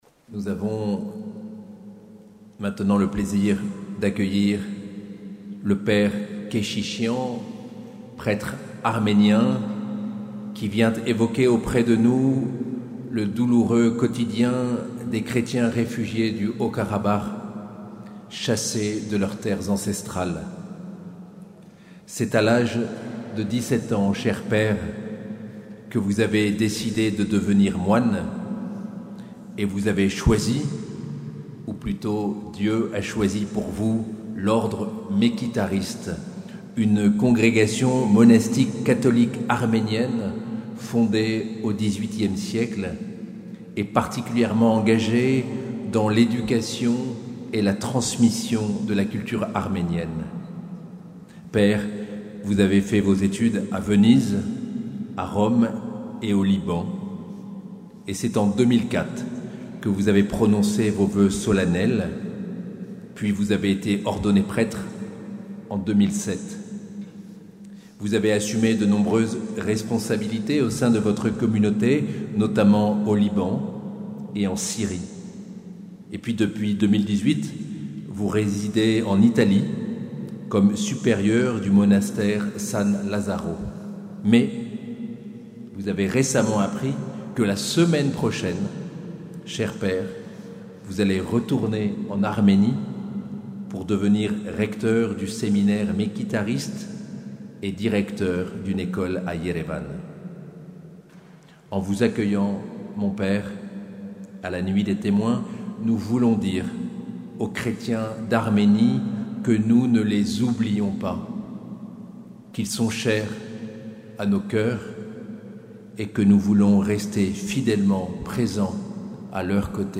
Nuit des Témoins le 21 novembre 2024 - Cathédrale de Bayonne